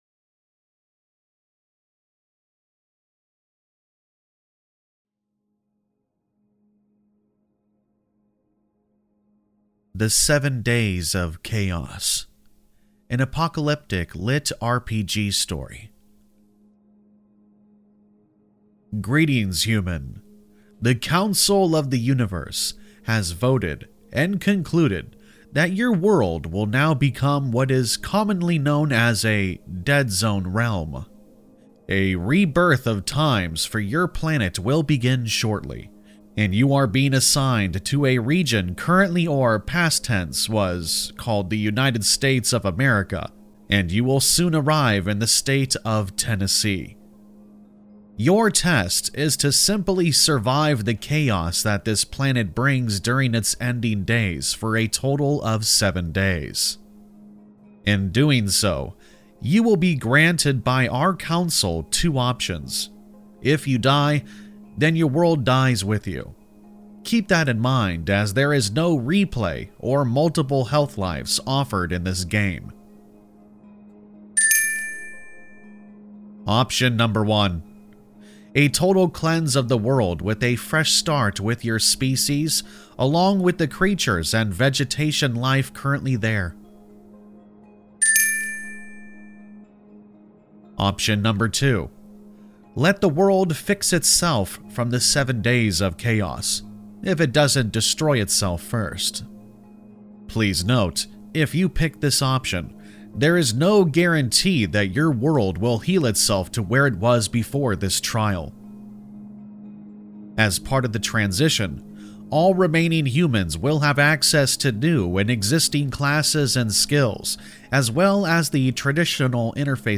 All Stories are read with full permission from the authors: Story Credit - Written at narrated by Campfire Tales